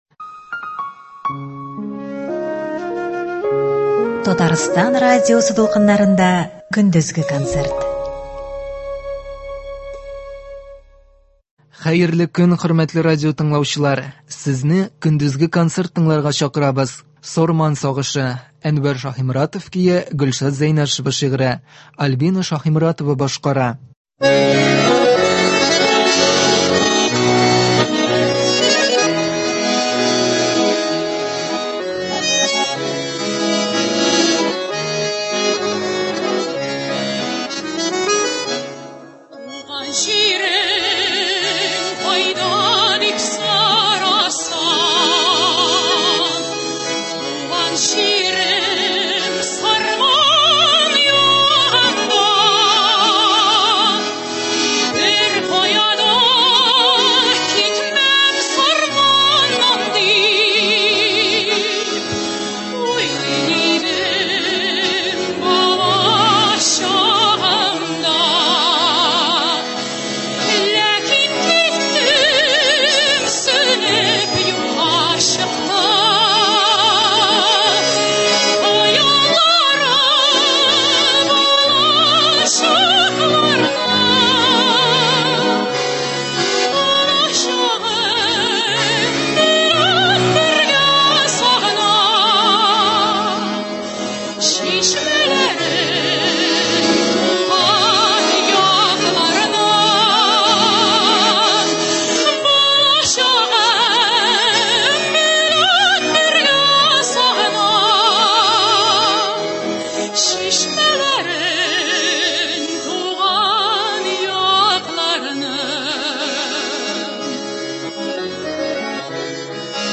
Көндезге тапшыруларны концерт белән дәвам иттерәбез.
Көндезге концерт (14.09.2021)